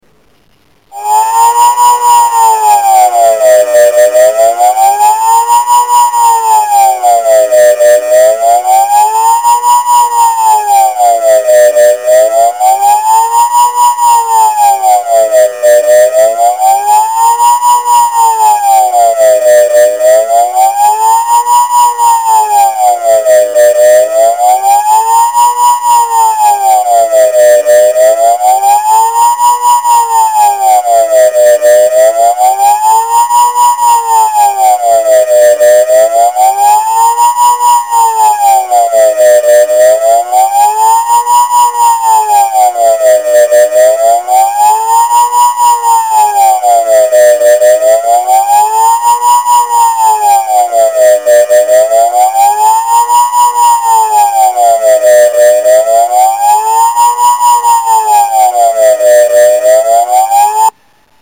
Test di funzionamento delle sirene
Si tratta di un test iniziale per valutare la copertura sonora del sistema ed il suo corretto funzionamento, le sirene saranno in grado di riprodurre differenti suoni in base alle differenti allerte.
SUONO-ALLARME-GENERALE.mp3